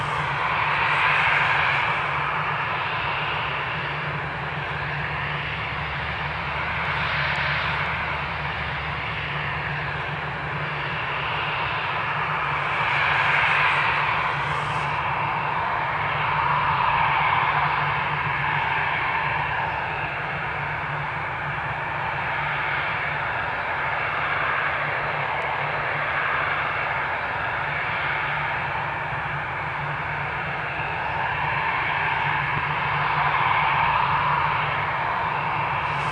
cfm-idleDist.wav